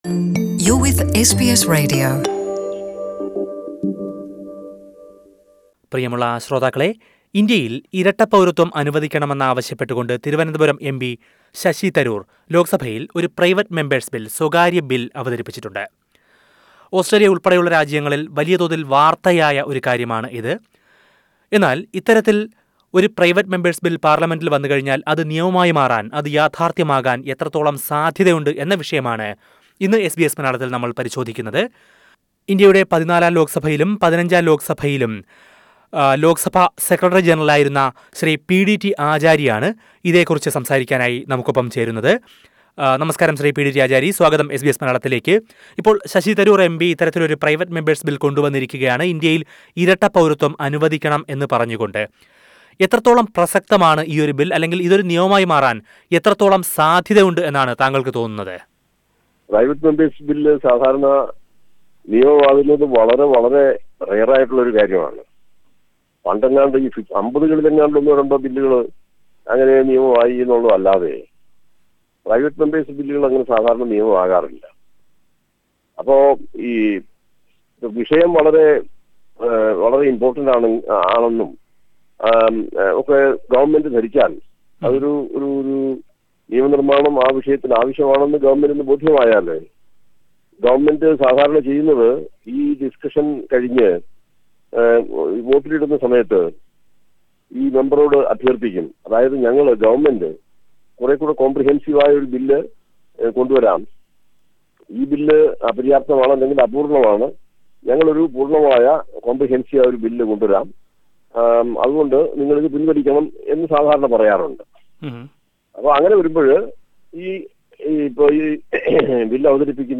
Former Secretary General of Indian Lok Sabha PDT Achary talks to SBS Malayalam.